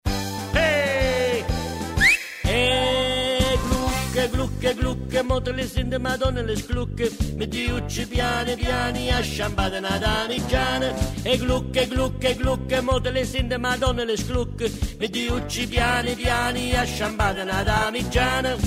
• Качество: 128, Stereo
свист
мужской голос
веселые
энергичные
Обманчивое начало рок трека